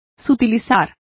Complete with pronunciation of the translation of refining.